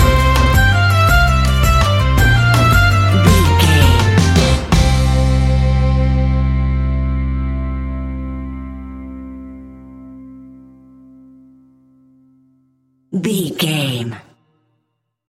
Mixolydian
sea shanties
acoustic guitar
mandolin
double bass
accordion